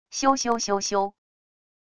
咻～咻～咻咻……wav音频